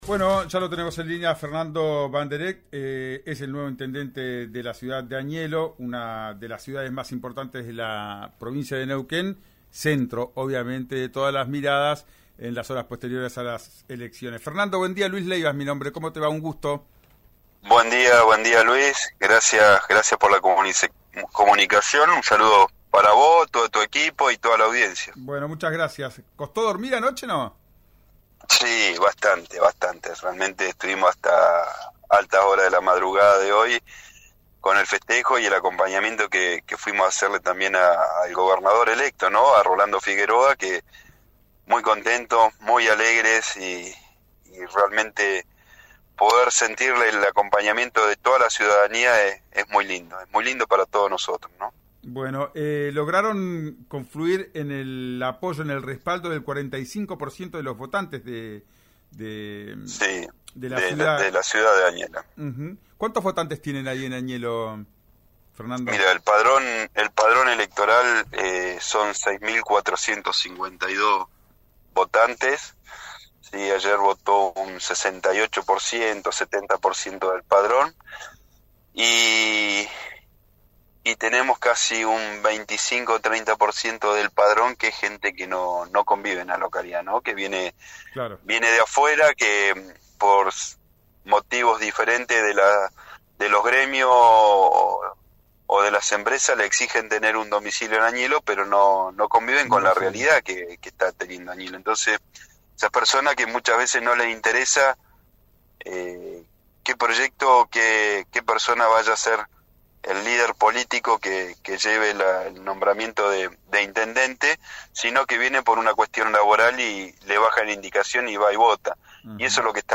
Escuchá a Fernando Banderet  en RÍO NEGRO RADIO: